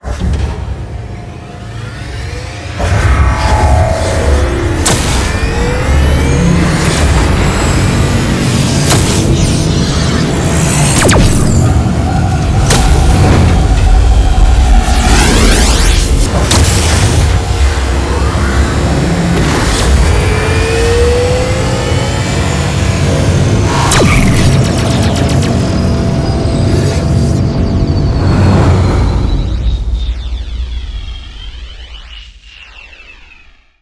wormhole.wav